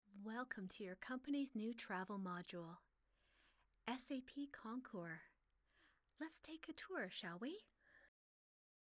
Female
Very clear enunciation.
E-Learning